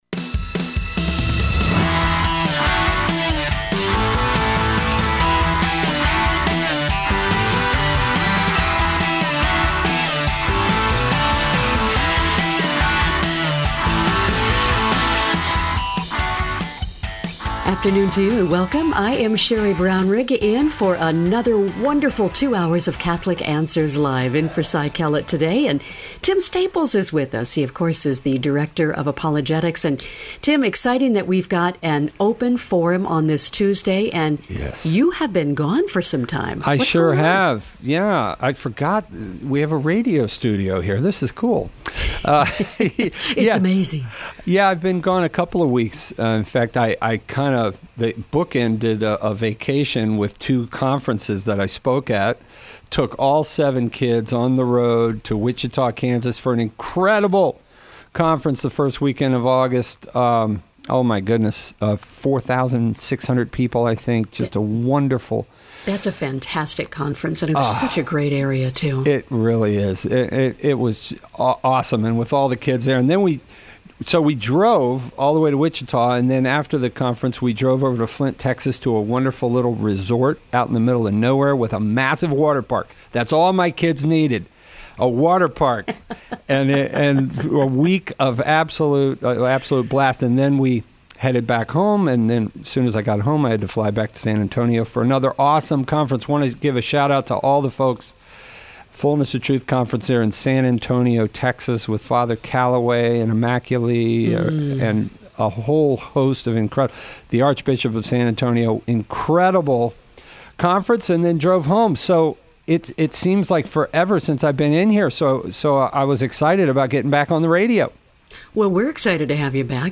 Who is the beloved disciple? How can I speak to my friends about Sola Scriptura? Callers choose the topics during Open Forum.